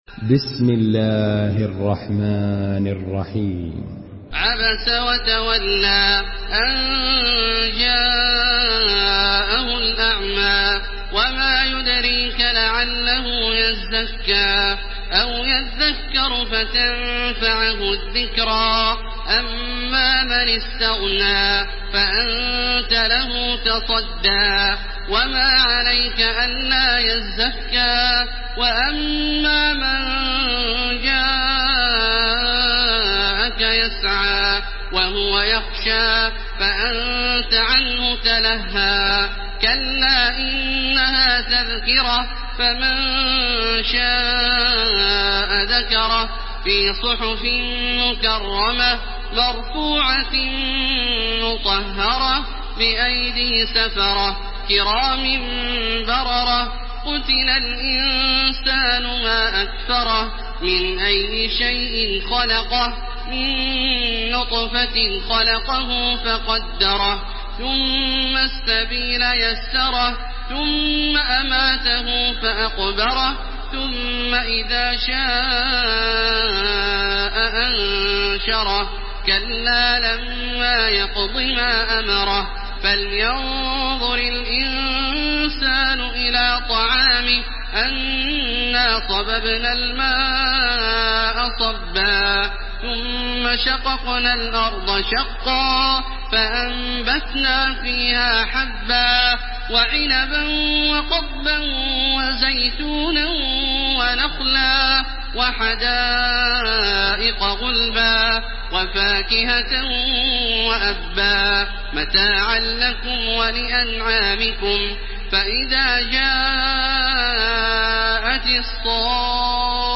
Surah Abese MP3 by Makkah Taraweeh 1430 in Hafs An Asim narration.
Murattal